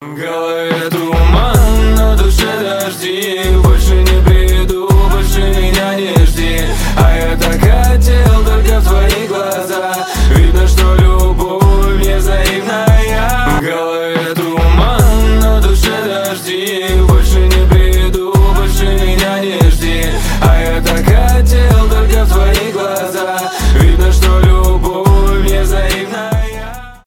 рэп , поп